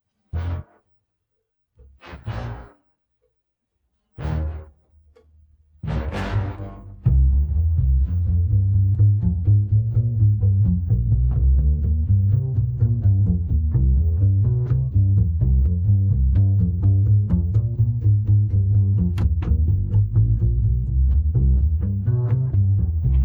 tmpd6_mx2pjday-of-sun-clip_bass.wav